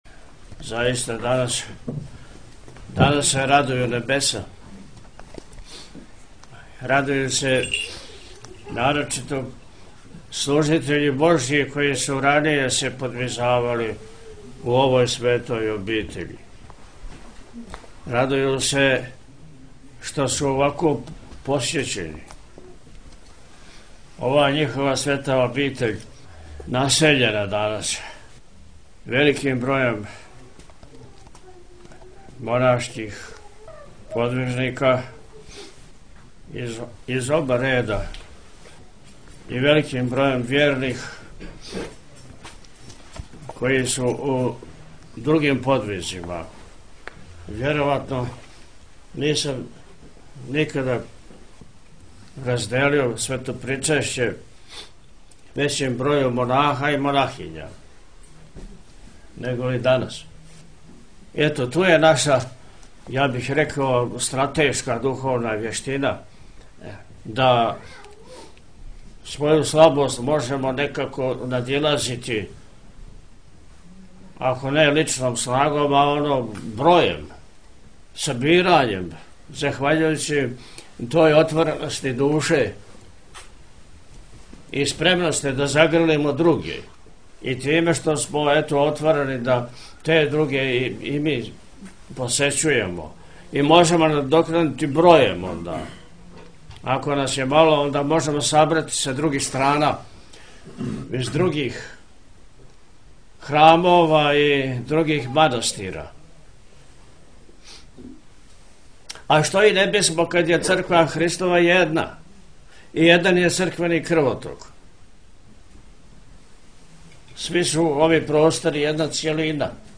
Поводом ктиторске славе манастира Давидовица код Бродарева – Преподобног монаха Давида, Његово високопреосвештенство Архиепископ и Митрополит милешевски г. Атанасије служио […]
Ктиторска слава манастира Давидовица
Davidovica-Beseda.m4a